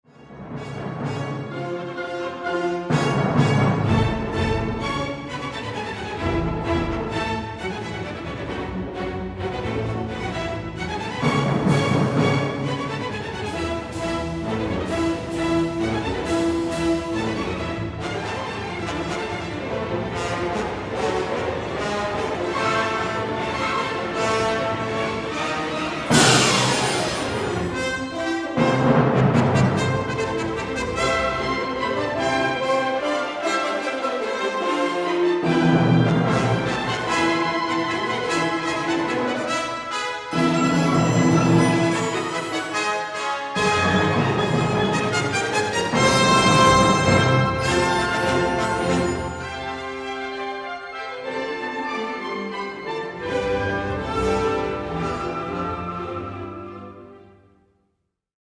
Wiele fragmentów zaskakuje dzikością i gwałtownością: